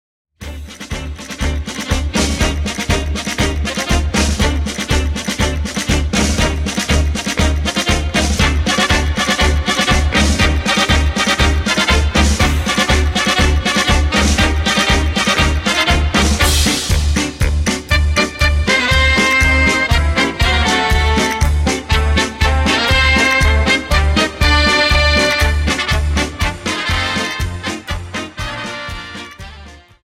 Dance: Paso Doble 60 Song
Phrasing, 3 Highlights